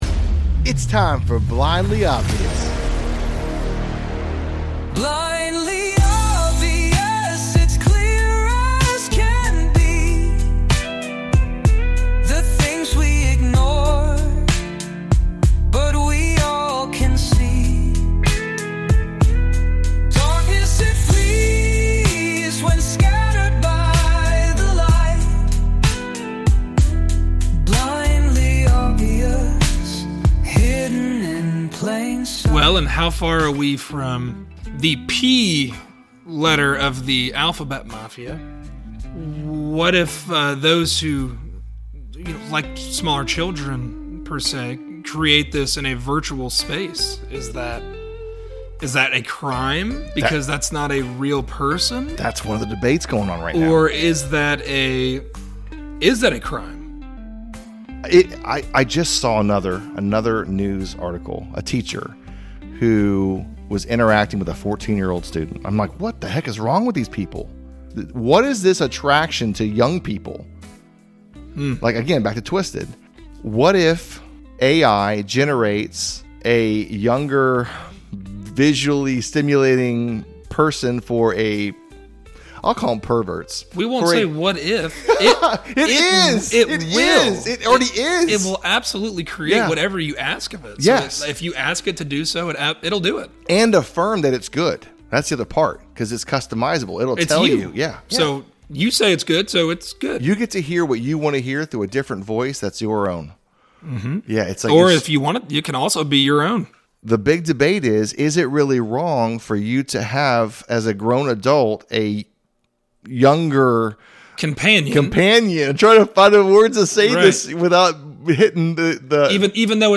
Join us for part two of a conversation on twisted times. In this episode, we explore how modern conversations and information flows are rarely straightforward. From subtle spins in the media to the evolving dynamics of human relationships, we ask: Are we twisting the narrative ourselves, or is it being shaped for us?